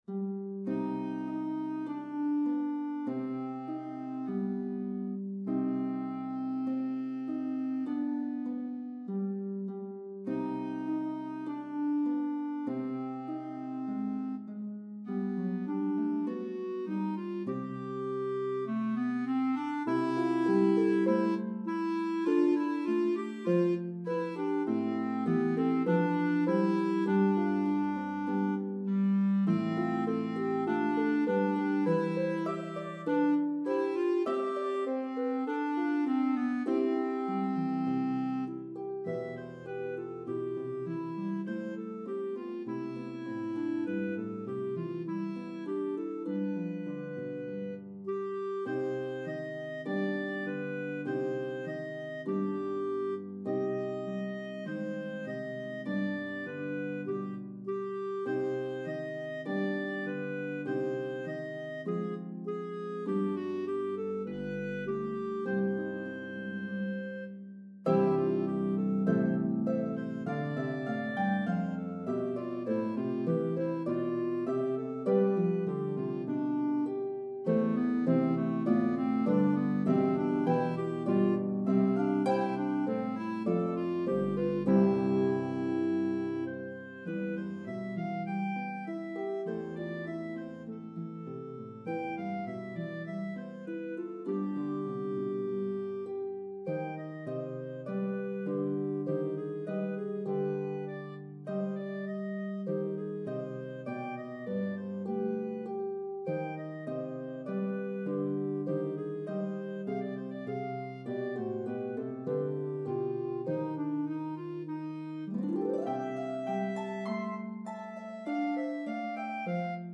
This cherished Carol recounts the birth of Christ.